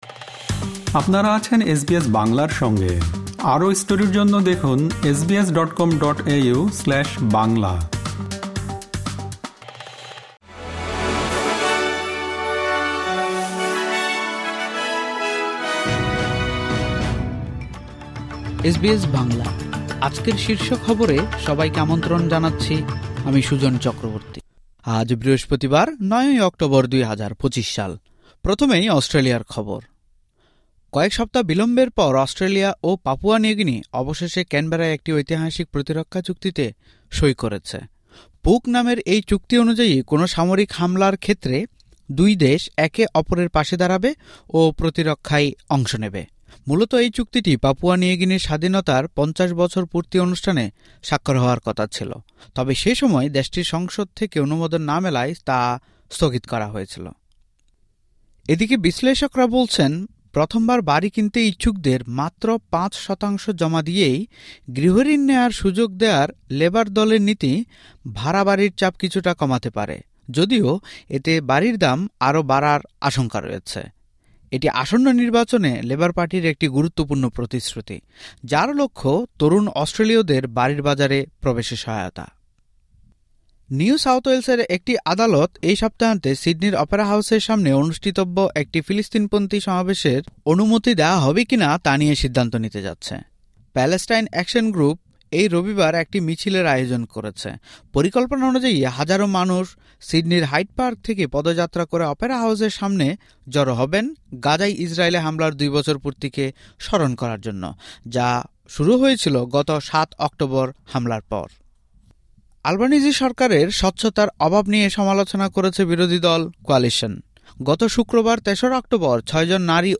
এসবিএস বাংলা শীর্ষ খবর: ৯ অক্টোবর, ২০২৫